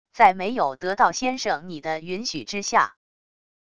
在没有得到先生你的允许之下wav音频生成系统WAV Audio Player